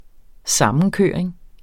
Udtale [ ˈsɑmənˌkøˀɐ̯eŋ ]